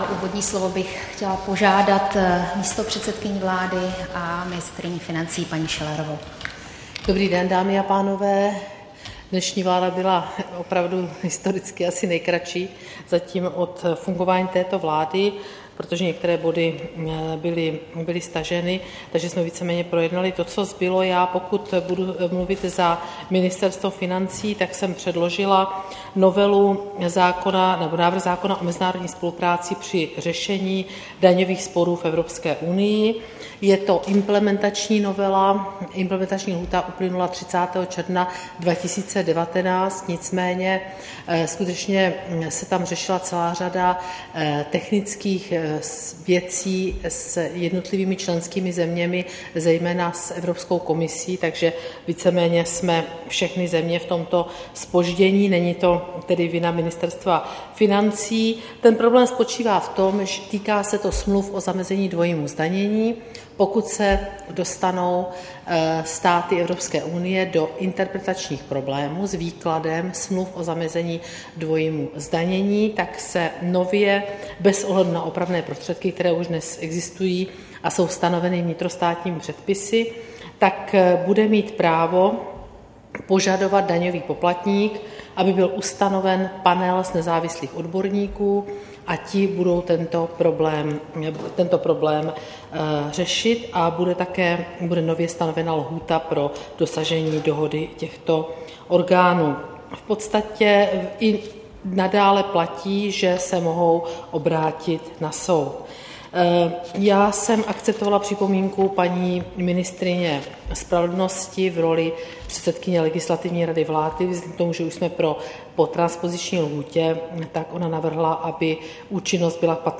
Tisková konference po jednání vlády, 1. července 2019